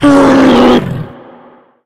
hit_1.ogg